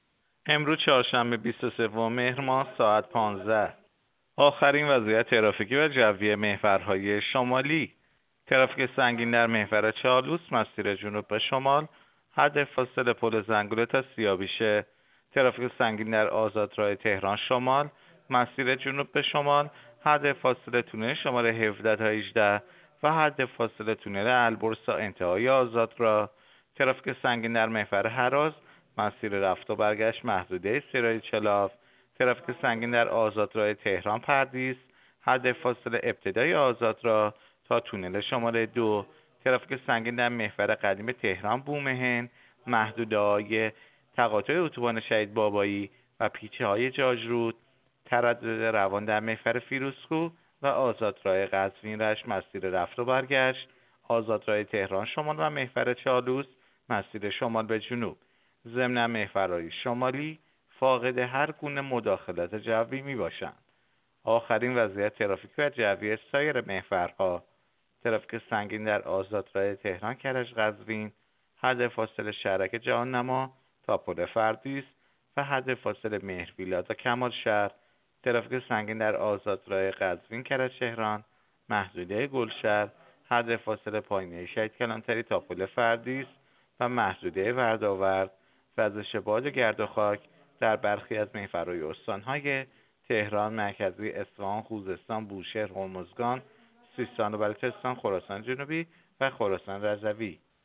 گزارش رادیو اینترنتی از آخرین وضعیت ترافیکی جاده‌ها ساعت ۱۵ بیست‌وسوم مهر؛